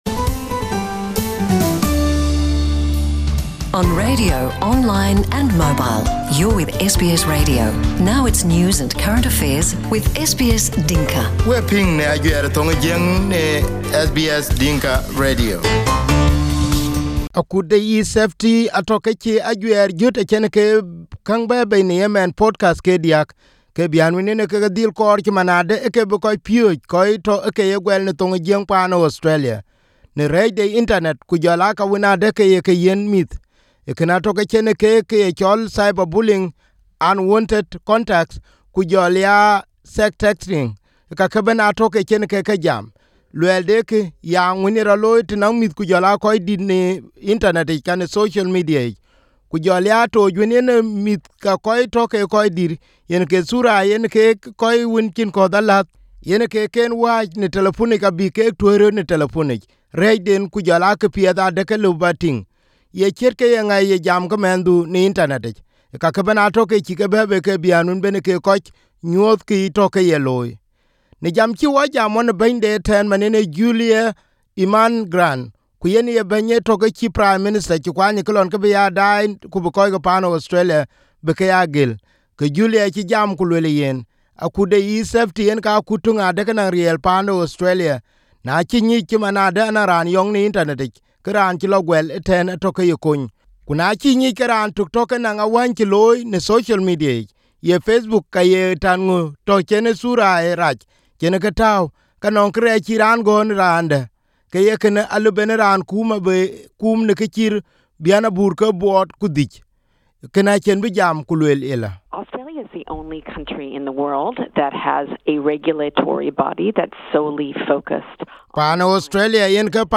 In this podcast, the esafety commissioner Julie Inman Grant warned those who are doing negatives things online.
The three podcasts are recorded as a conversation between two people talk about cyberbullying, unwanted contact and Sexting.